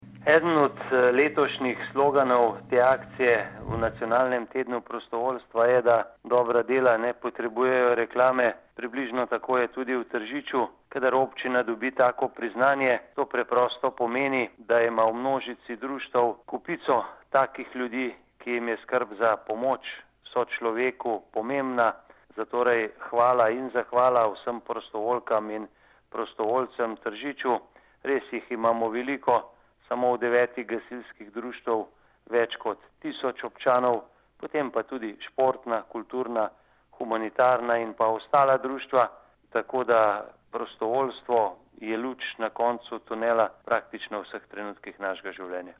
izjava_mag.borutsajoviczupanobcinetrzicoprostovoljstvu.mp3 (1,1MB)